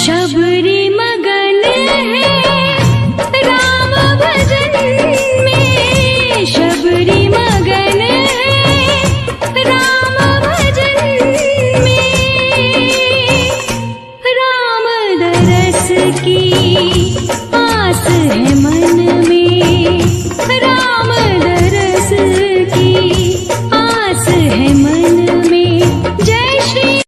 • Calm and peaceful music
• Category: Devotional / Ram Bhajan
• Quality: High Quality / Clear Sound